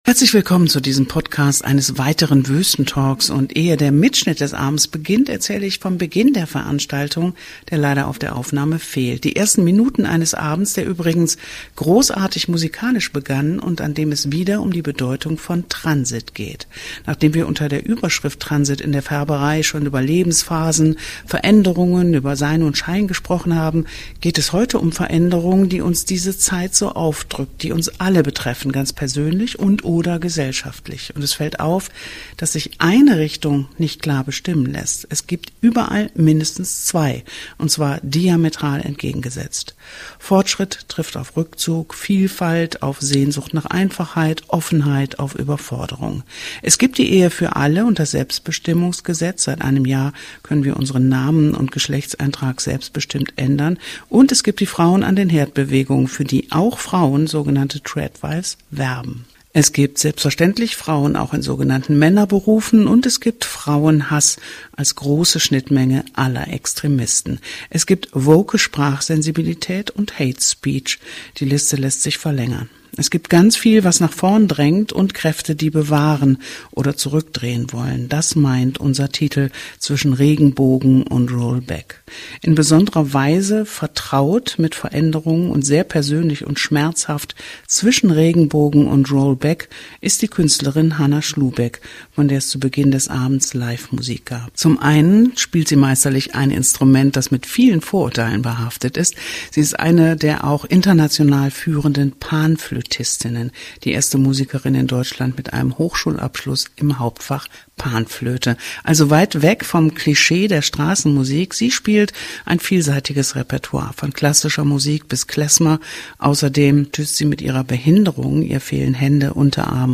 Ein spannendes Gespräch mit Gästinnen und Nachbar*innen. Wir haben mitgeschnitten.